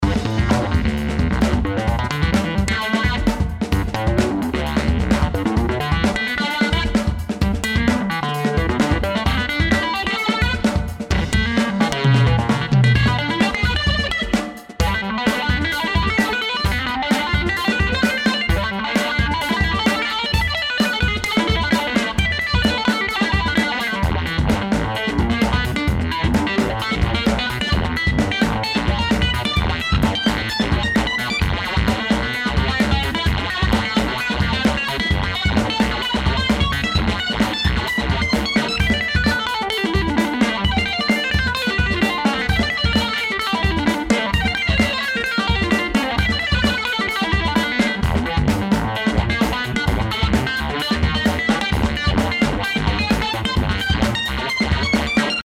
Daraus ergibt sich ein weicher, sanfter Release.
Er bietet die Modi Phaser und Unvibe.
Wenn man beim Amp Gas gibt (mittels Drive und Boost) kommt Stimmung im Wohnzimmer auf, und die Teller in der Vitrine beginnen zu klirren.